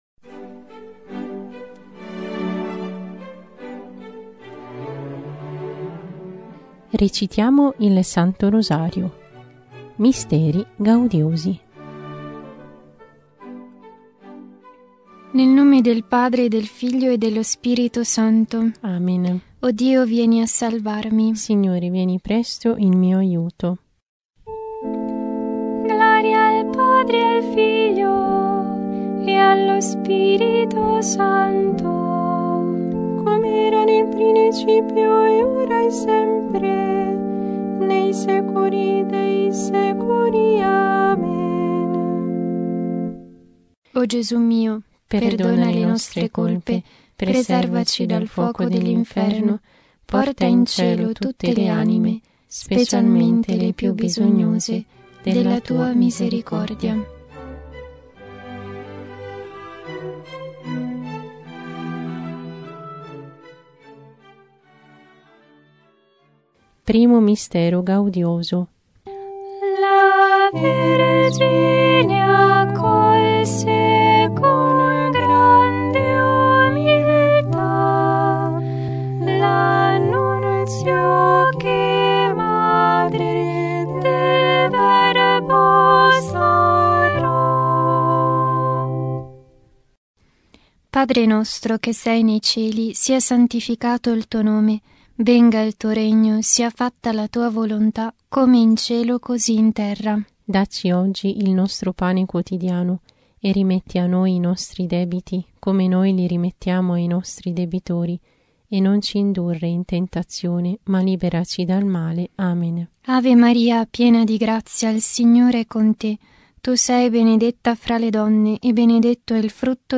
Genere: Preghiere.